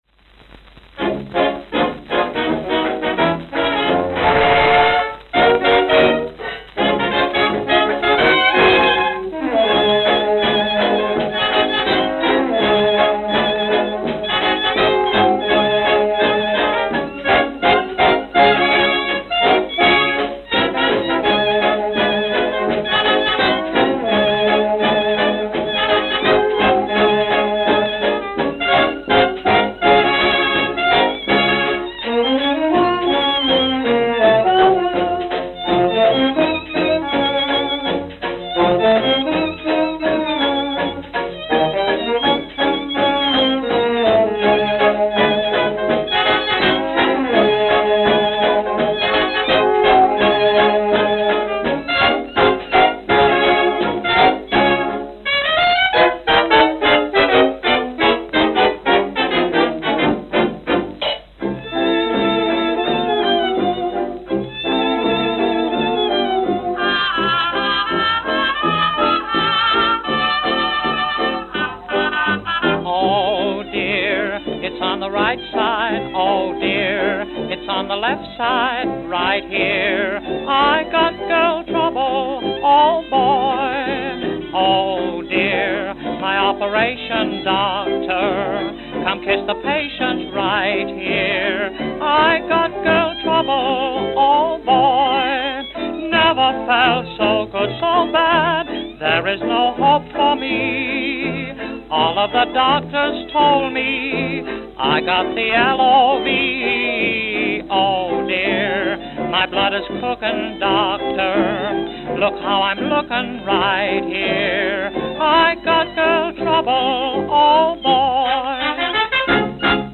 Electrical Recordings